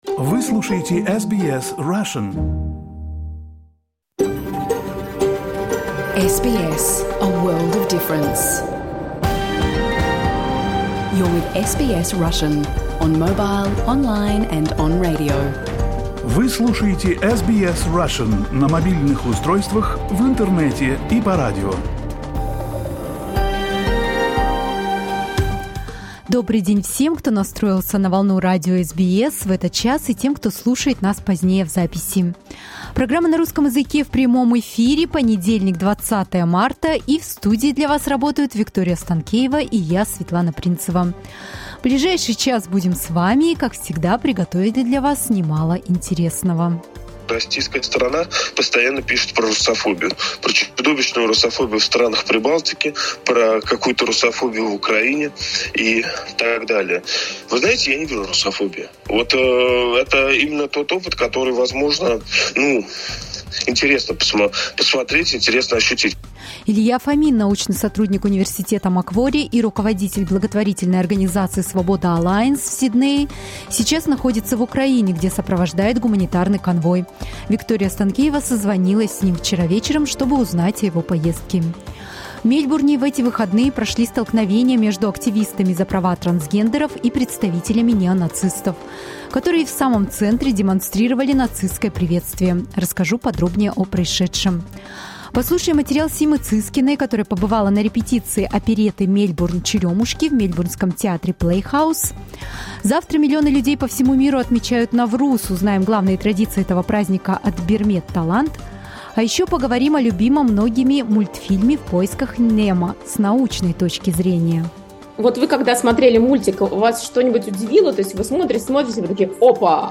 You can listen to SBS Russian program live on the radio, on our website and on the SBS Radio app.